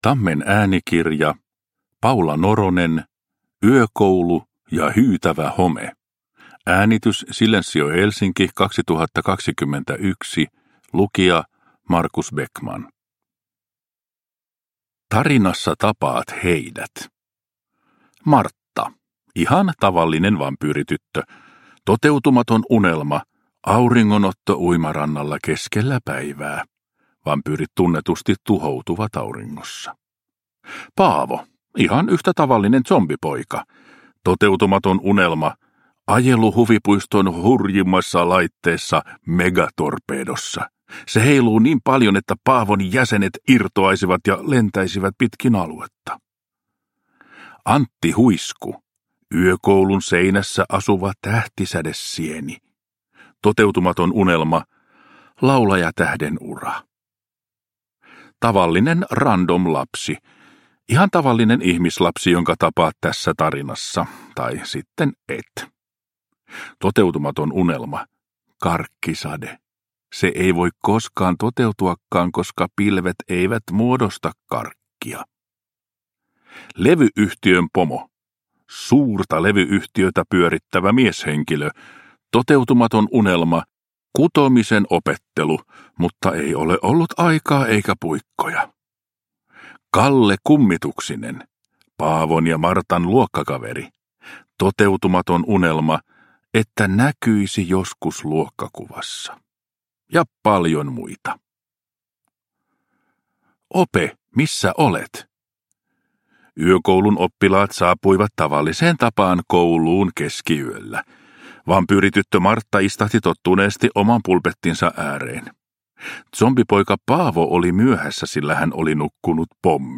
Yökoulu ja hyytävä home – Ljudbok